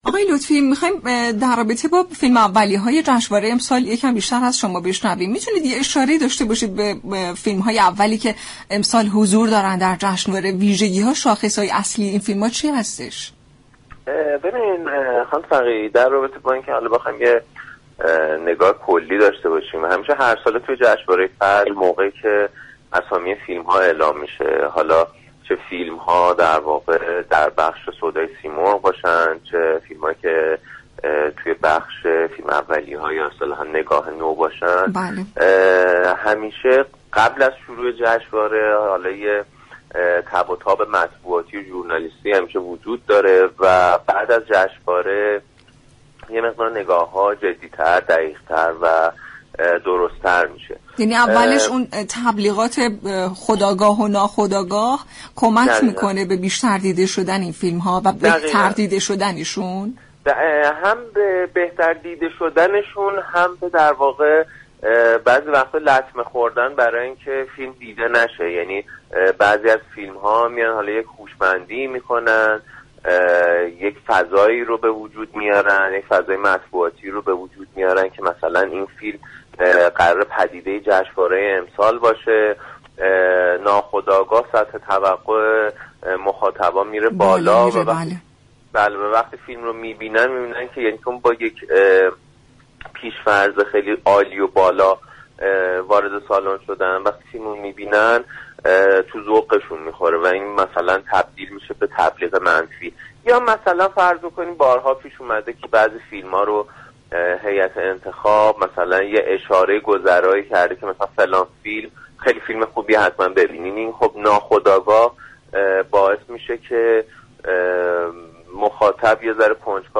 این گفت و گو را در ادامه می شنوید : و/م